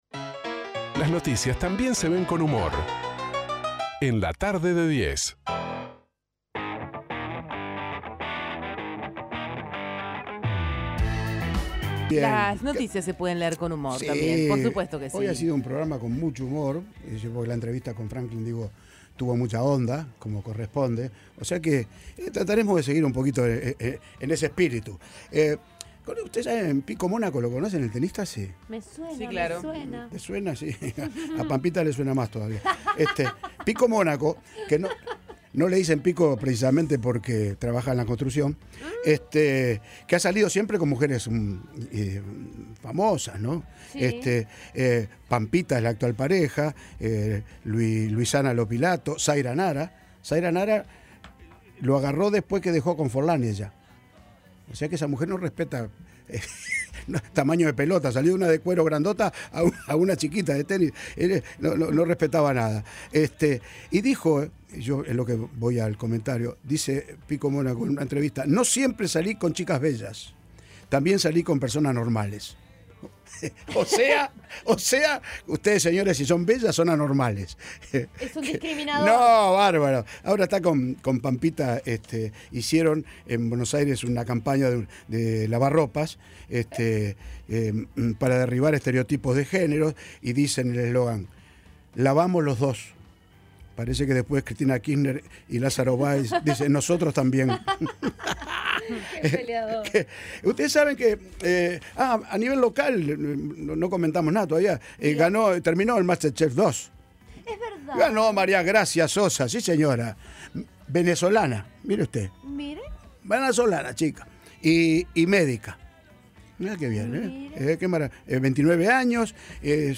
Las noticias son leídas con humor